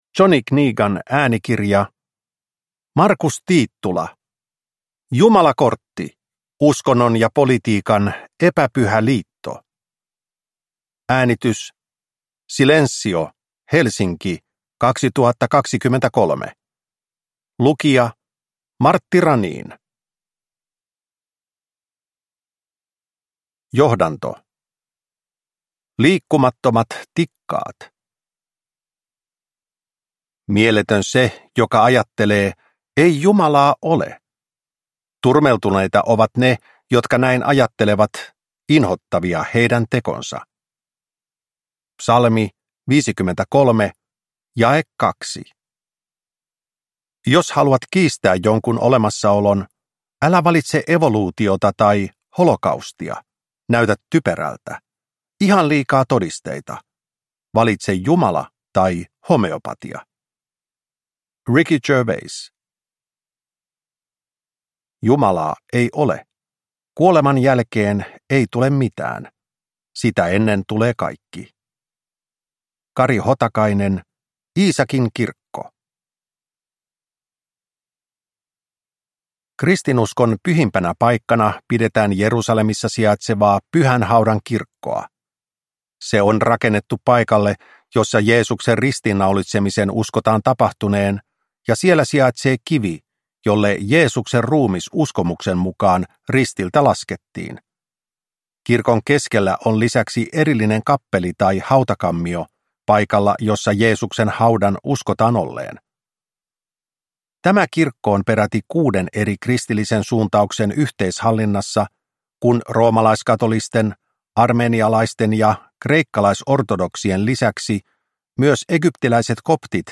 Jumalakortti – Ljudbok – Laddas ner